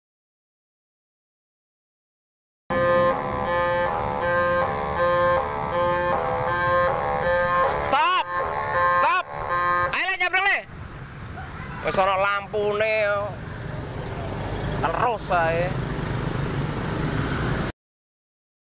Lampu merah tot tot sound effects free download